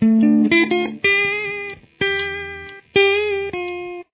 Guitar 1 :
Here is the lead part above by itself :